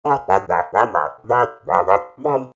COG_VO_murmur.ogg